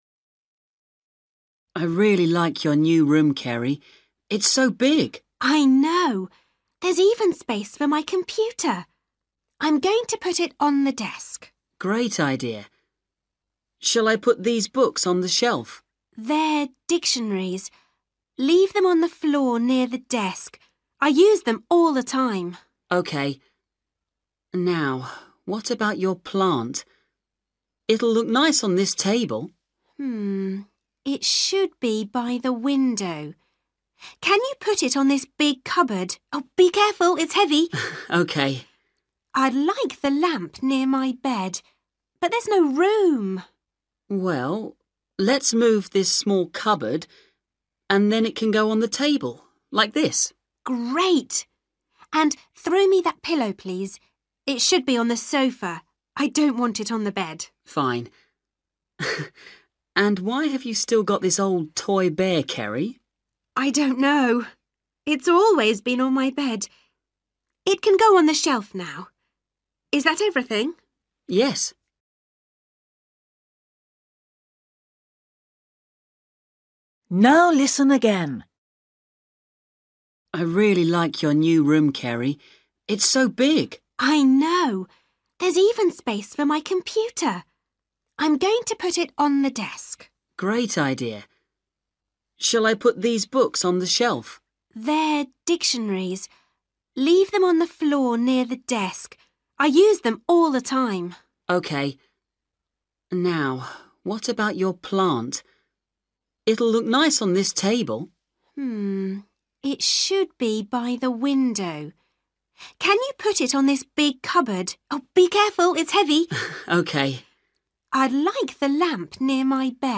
Bài tập luyện nghe tiếng Anh trình độ sơ trung cấp – Nghe cuộc trò chuyện và chọn câu trả lời đúng phần 64
Luyện nghe trình độ A2